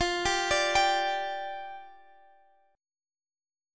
G5/F chord